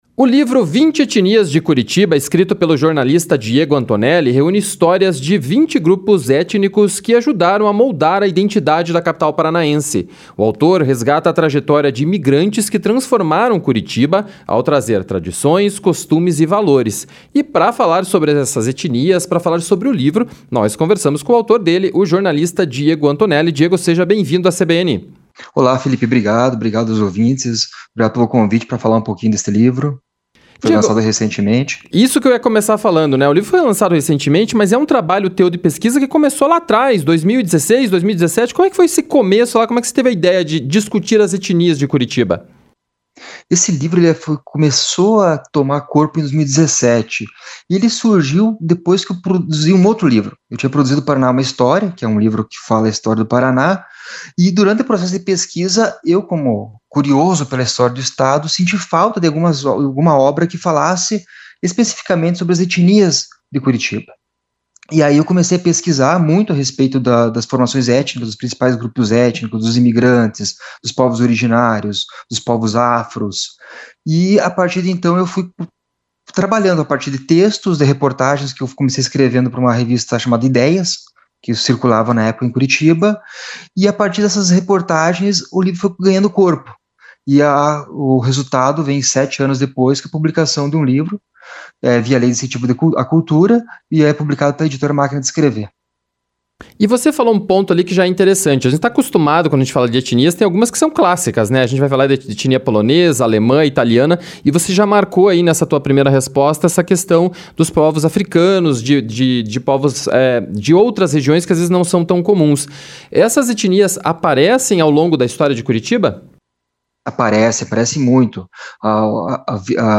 ENTREVISTA-LIVRO-ETNIAS-DE-CURITIBA.mp3